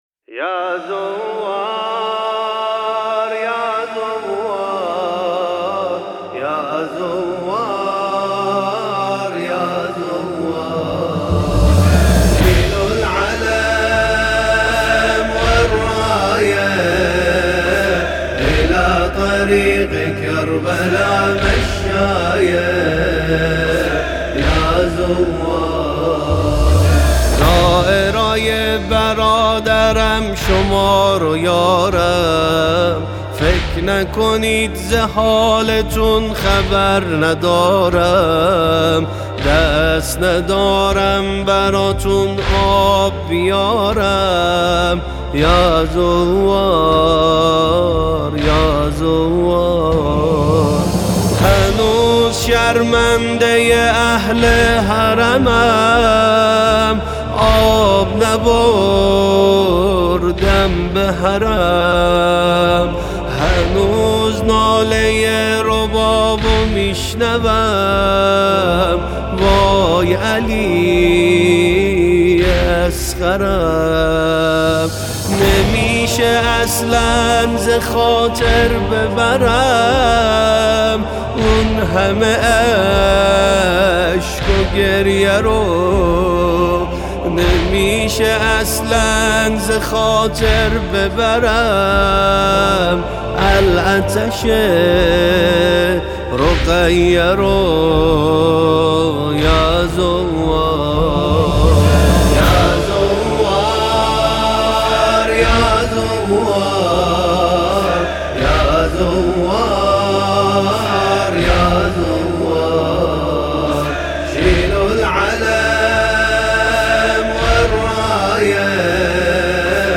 الی طريق كربلا مشايه - مداحی استودیویی